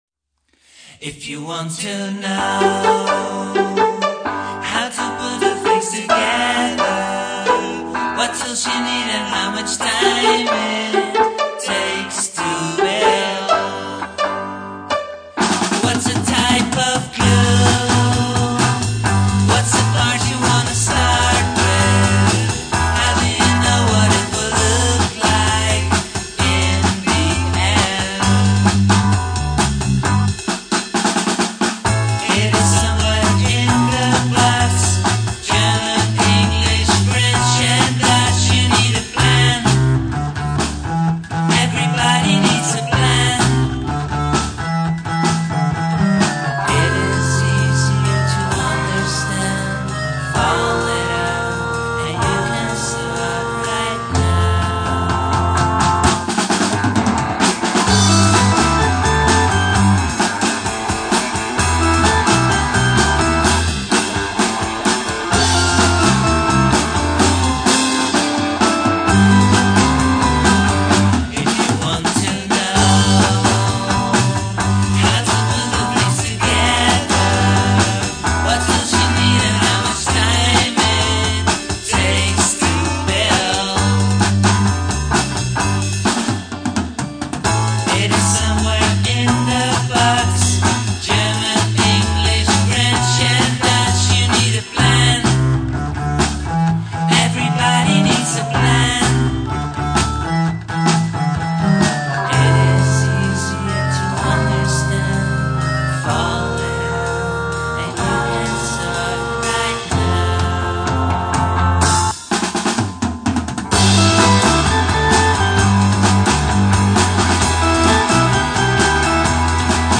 amazing harmonica solo